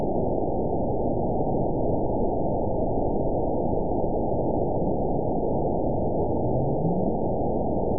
event 910928 date 02/01/22 time 18:11:09 GMT (3 years, 3 months ago) score 9.61 location TSS-AB01 detected by nrw target species NRW annotations +NRW Spectrogram: Frequency (kHz) vs. Time (s) audio not available .wav